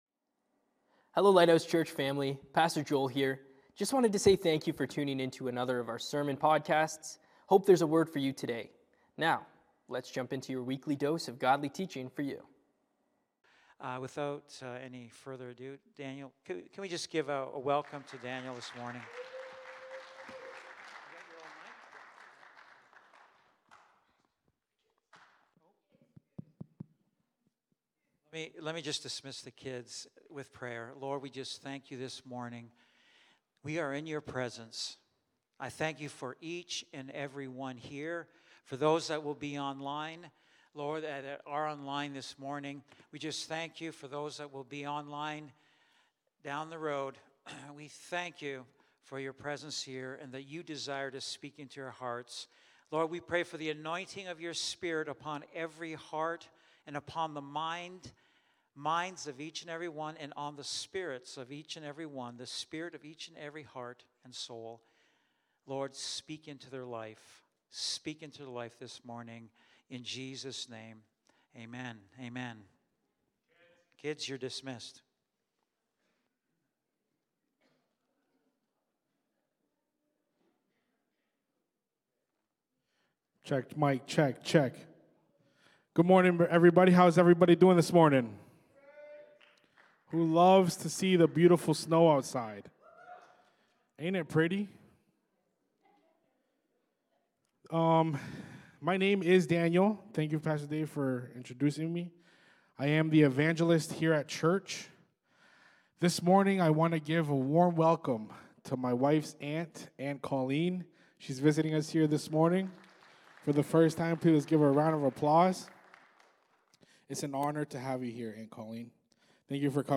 Lighthouse Niagara Sermons Who’s Calling The Plays In Your Life?